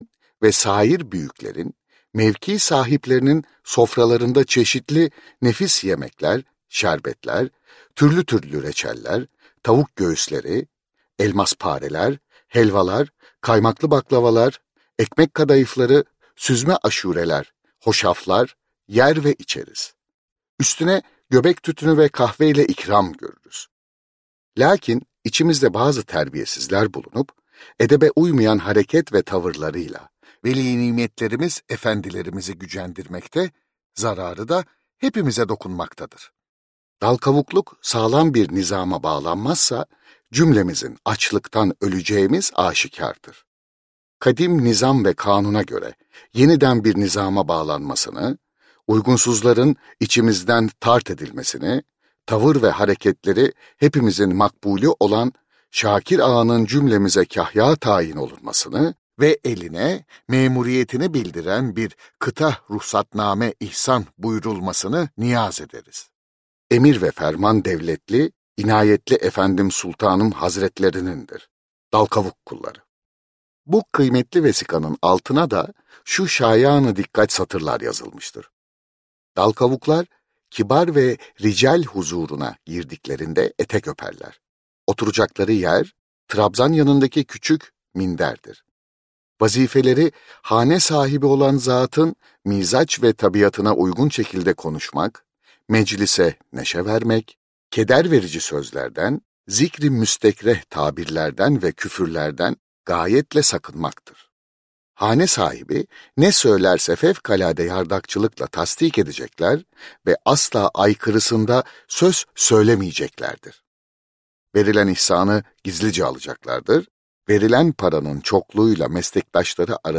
Tarihimizde Garip Vakalar - Seslenen Kitap